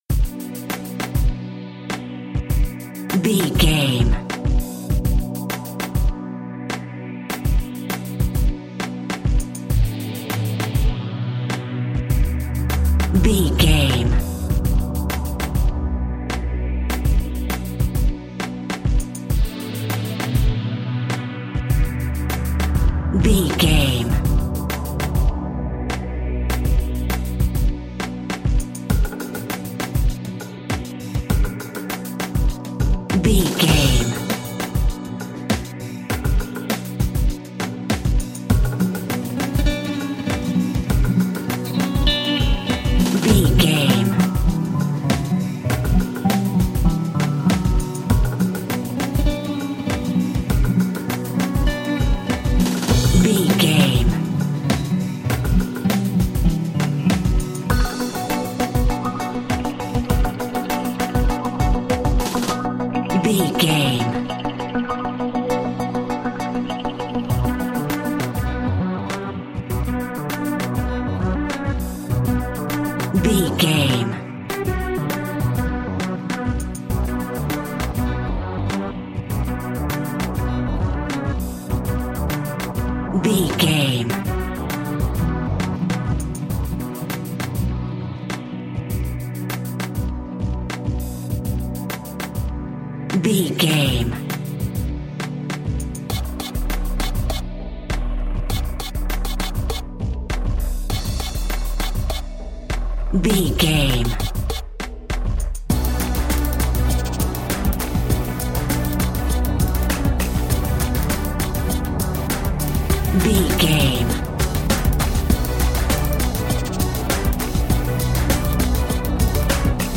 Sweeping Hip Hop Synths.
Aeolian/Minor
electronic
techno
drone
glitch
synth lead
synth bass